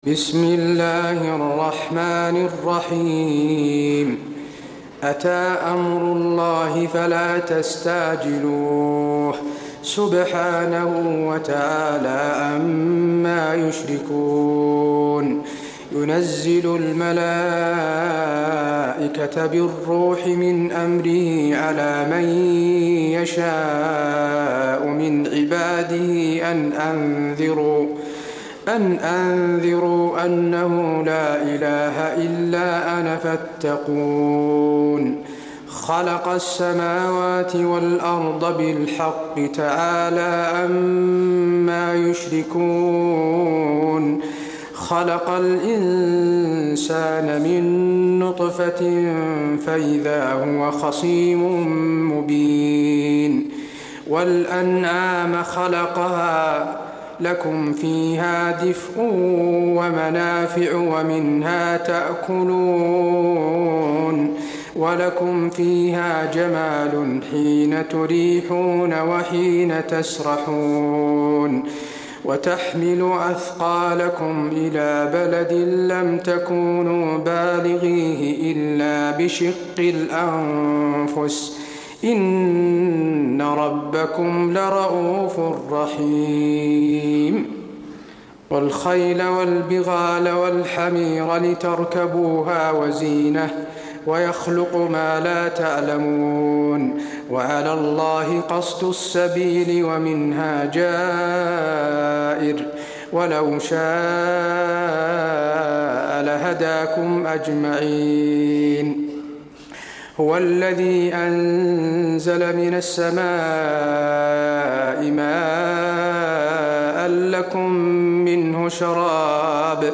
تراويح الليلة الثالثة عشر رمضان 1426هـ من سورة النحل (1-44) Taraweeh 13 st night Ramadan 1426H from Surah An-Nahl > تراويح الحرم النبوي عام 1426 🕌 > التراويح - تلاوات الحرمين